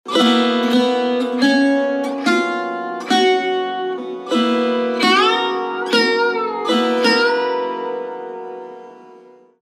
Raga
Shudh Sarang (Aroha)